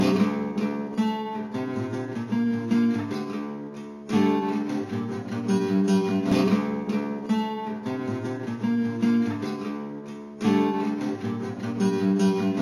原声吉他曲
描述：152 bpm
Tag: 152 bpm Rap Loops Guitar Acoustic Loops 2.13 MB wav Key : Unknown